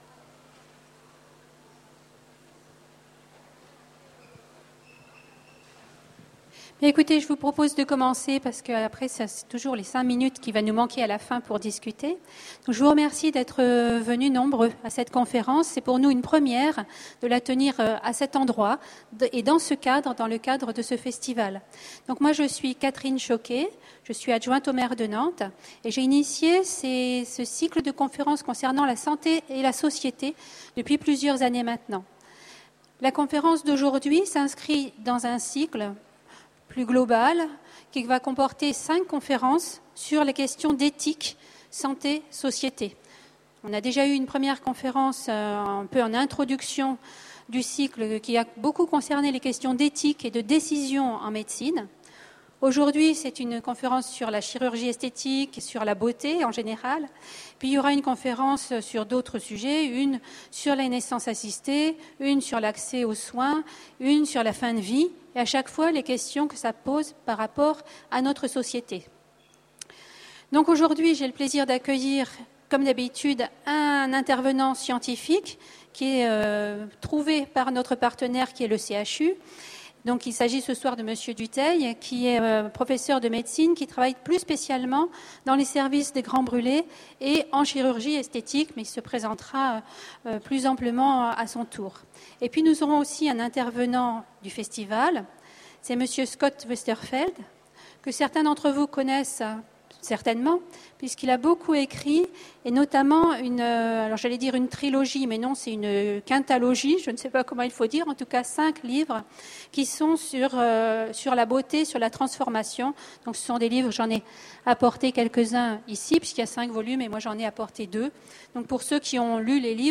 Voici l'enregistrement de la conférence Serons-nous tous beaux en 2100 ? aux Utopiales 2010.